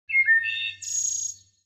دانلود آهنگ پرنده 20 از افکت صوتی انسان و موجودات زنده
دانلود صدای پرنده 20 از ساعد نیوز با لینک مستقیم و کیفیت بالا
جلوه های صوتی